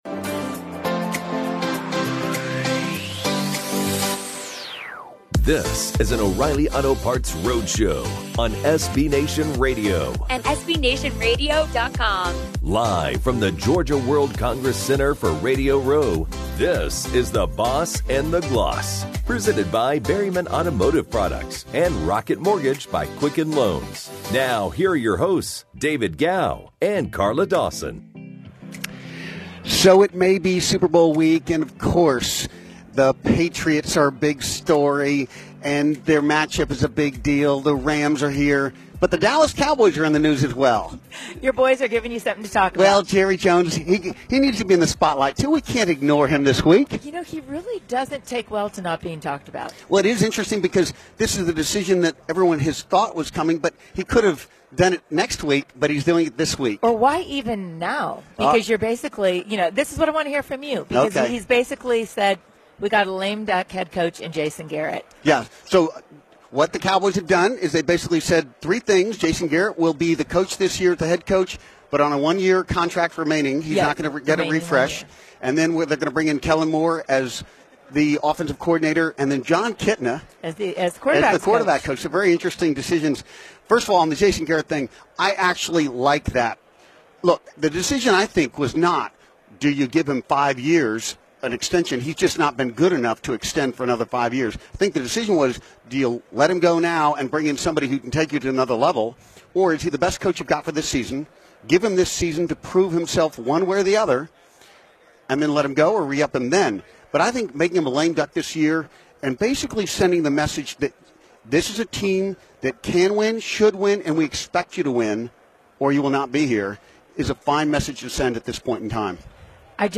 The Boss & The Gloss SBLIII Radio Row Guest: Panthers TE Greg Olsen